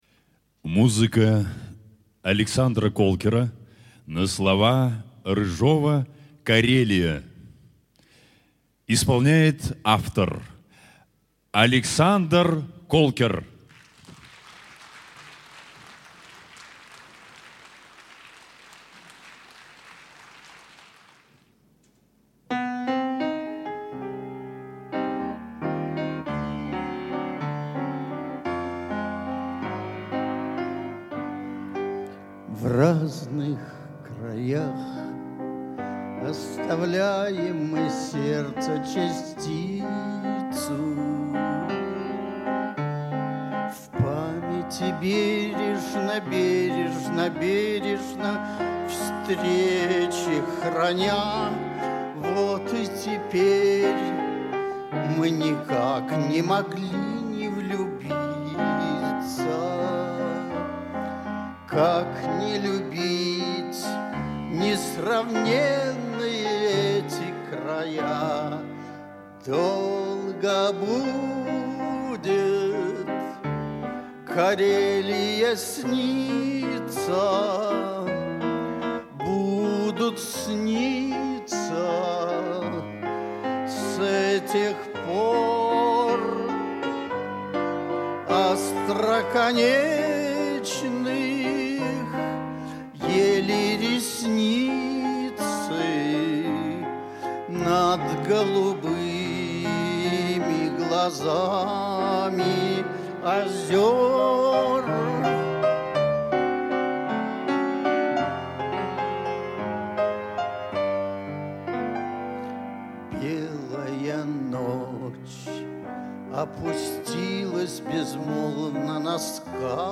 Редкая запись из концертного зала "Александровский".
на рояле